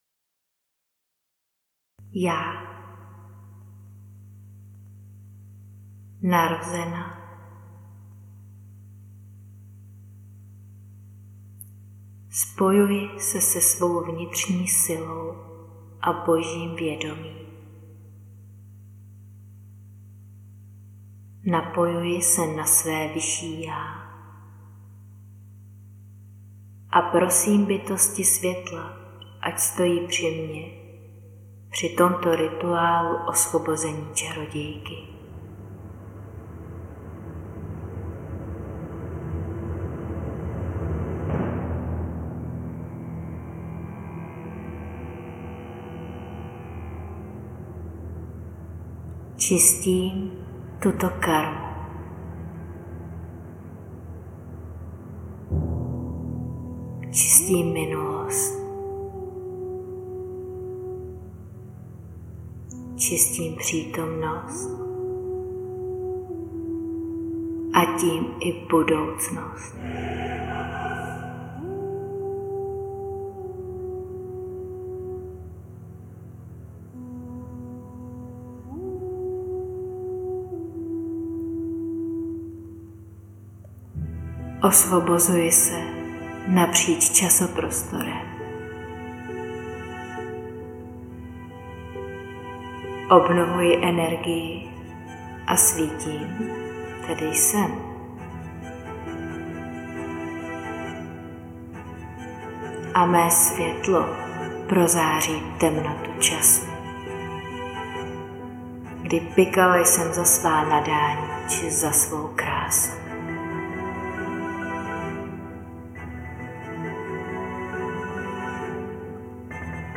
MAGICKÁ MEDITACE Popis Milá ženo, vítám Tě u Tvého rituálu “Osvobození Čarodějky“!